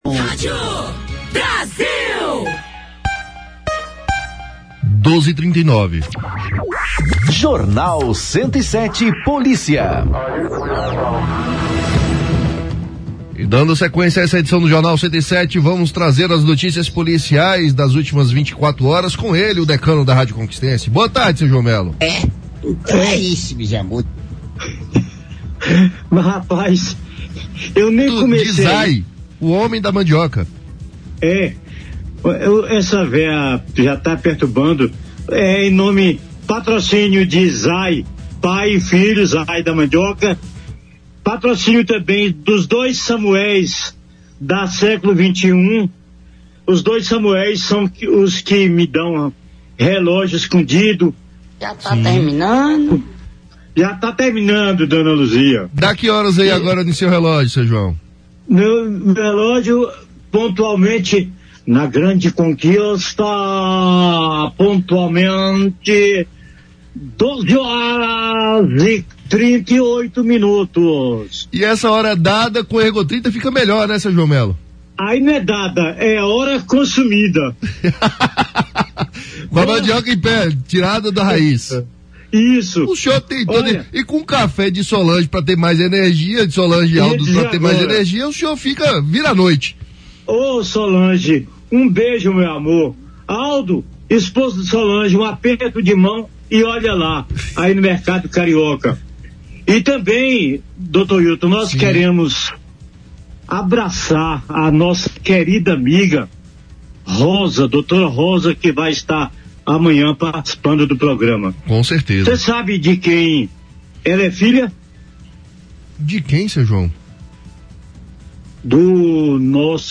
O Jornal 107, da Rádio Brasil, trouxe nesta quarta-feira (22) um resumo das últimas ocorrências policiais, com destaque para duas mortes. Um homem morreu na Feirinha da Patagônia, Zona Oeste de Vitória da Conquista, durante a madrugada fria, quando a sensação térmica chegou aos 8 °C. O Serviço de Atendimento Móvel de Urgência (SAMU) confirmou o óbito sem encontrar sinais de violência. O corpo segue em necropsia no Instituto Médico Legal (IML) para apontar se o frio extremo causou a morte.